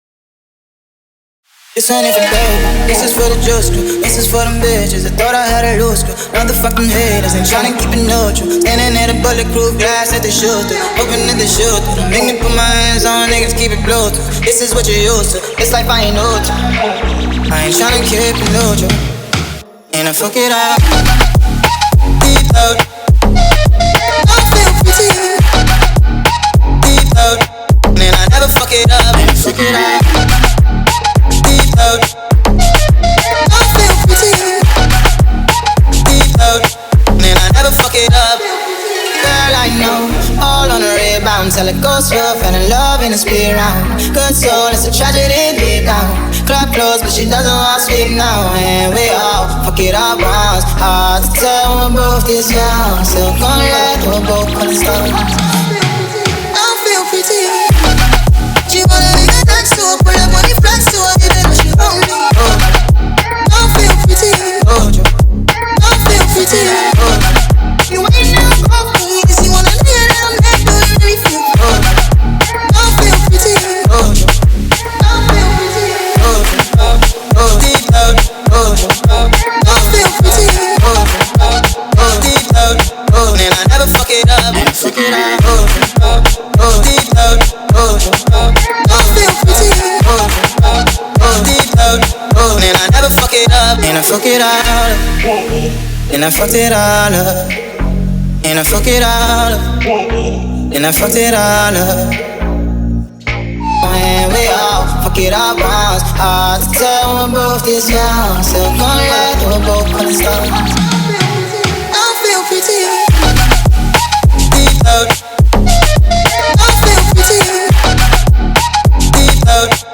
динамичная и атмосферная композиция